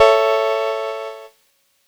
Cheese Chord 03-A2.wav